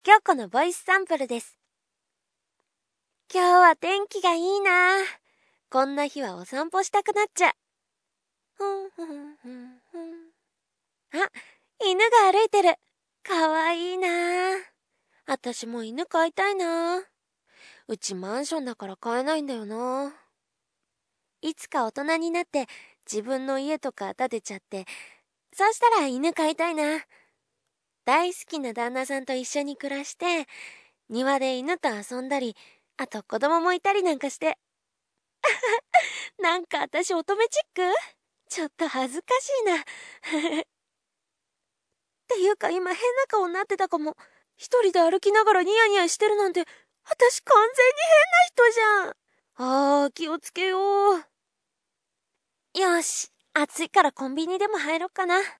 コメント 　サンプルはファイルサイズを軽くするために少し音質が悪くなってますので、実際はもう少しクリアだと思います。
ヒロインタイプですかね。